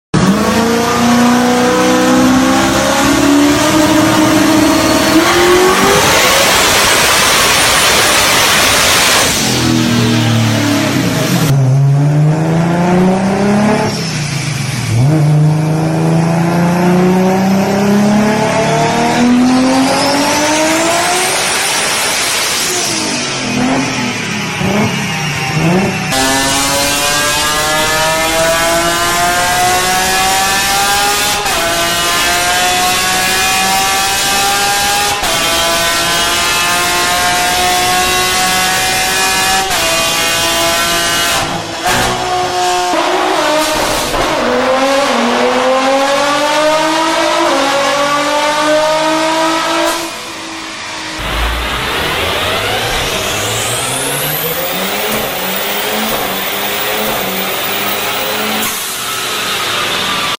Top 5 Scariest Dyno Pulls Sound Effects Free Download